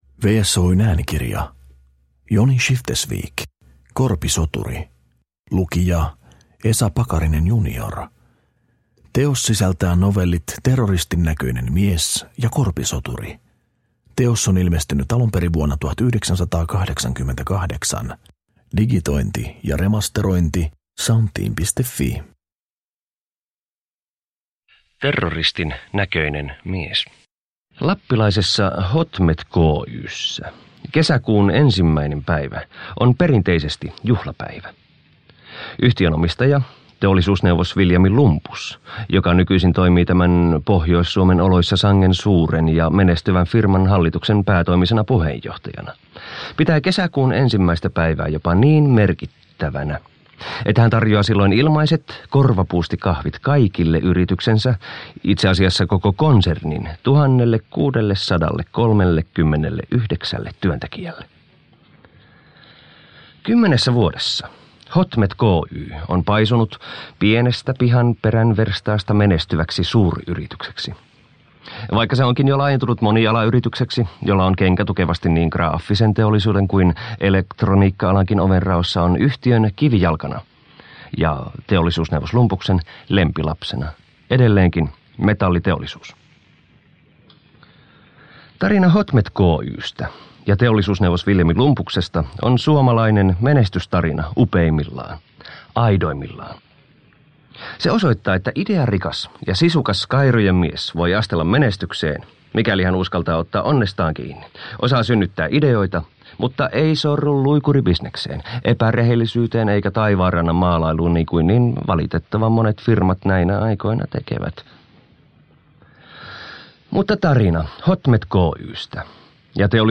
Korpisoturi – Ljudbok